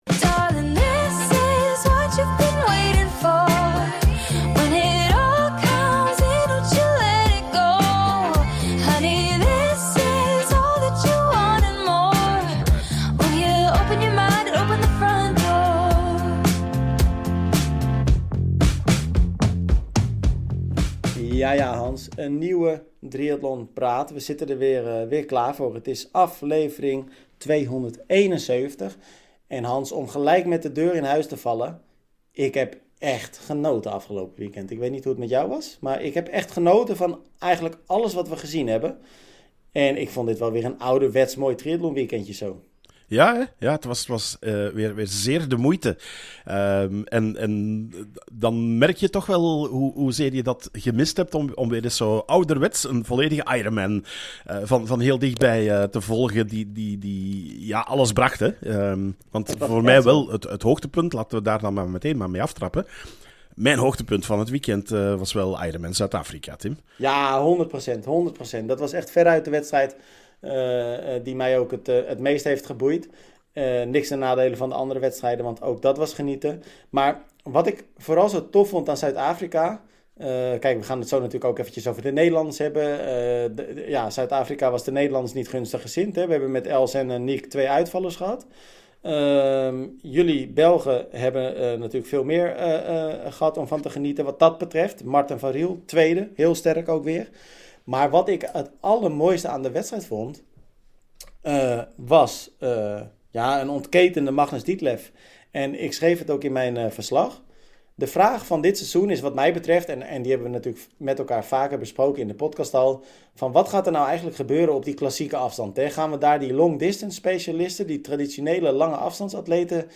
En de naam zegt het al: hier komt de redactie aan het woord over het laatste, maar vooral ook het meest spraakmakende en/of meest gelezen nieuws van de afgelopen week. Dat voorzien we dit keer van een persoonlijke mening, meer achtergrond of gewoon onze visie.